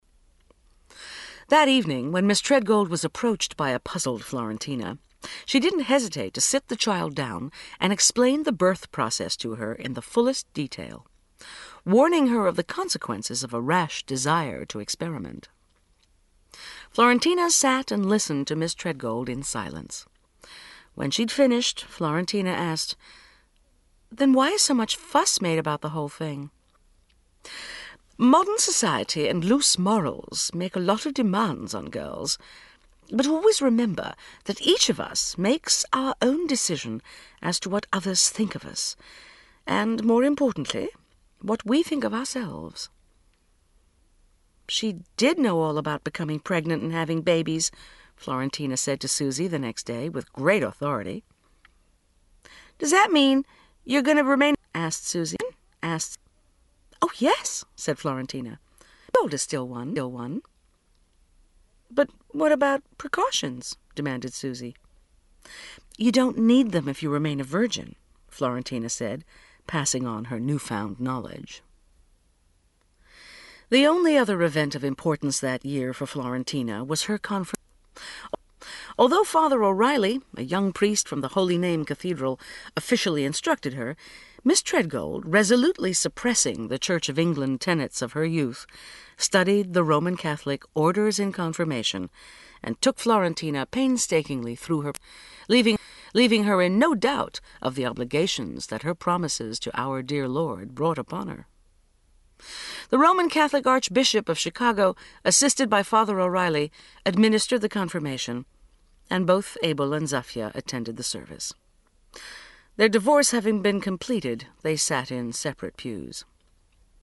37serv Audiobooks/Jeffrey Archer - Prodigal Daughter (1982) (96)